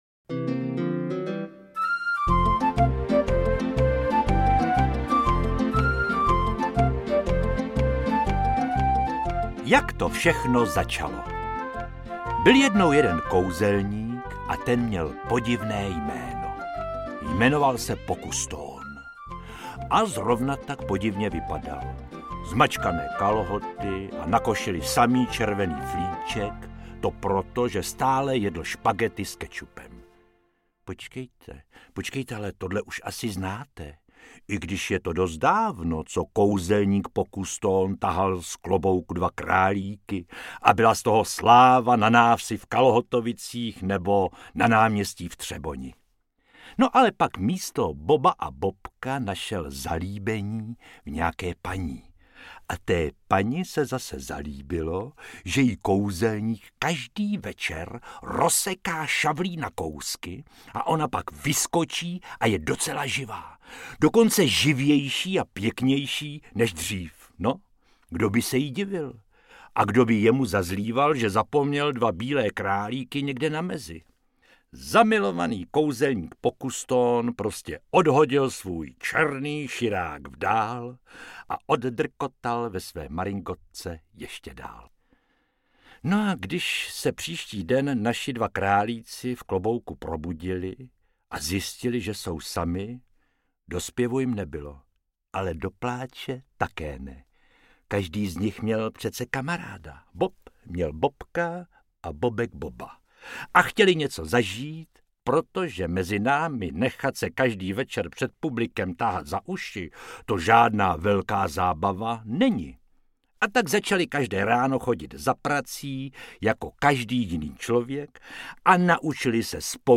Interpret:  Josef Dvořák
Dalších pět příběhů Boba a Bobka, opět v interpretaci Josefa Dvořáka, který jim propůjčil svůj hlas i na televizní obrazovce, nabízí náš nový titul s obrázkem obou králičích protagonistů a nezbytného cylindru z pera výtvarníka Vladimíra Jiránka na...
AudioKniha ke stažení, 6 x mp3, délka 1 hod. 15 min., velikost 68,2 MB, česky